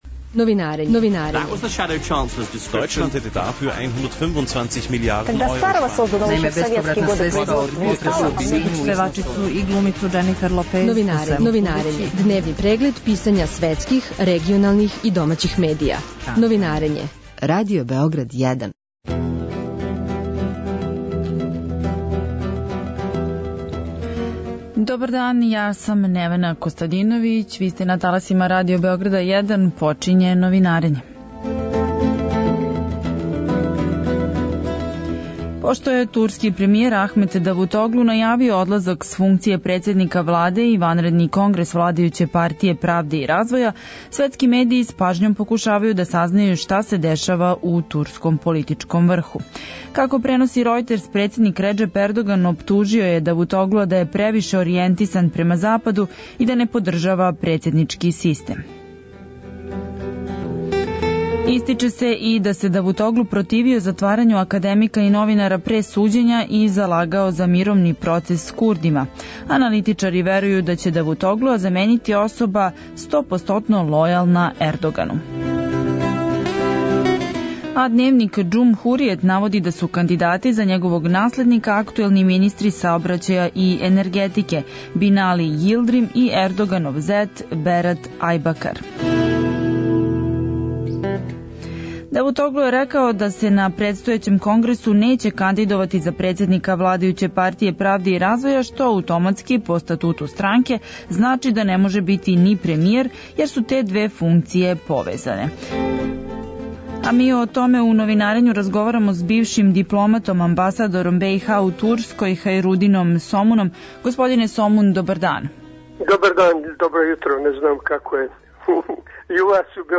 Гост Новинарења је бивши дипломата, амабасадор Босне и Херцеговине у Турској, Хајрудин Сомун.